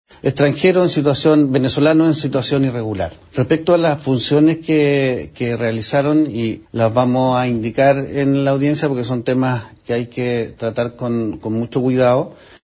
El fiscal ECOH, Héctor Barros, indicó que el imputado es un hombre de entre 20 y 30 años, venezolano y con su situación irregular en el país. Agregó que será en la audiencia de formalización donde se dará a conocer cuál fue su rol.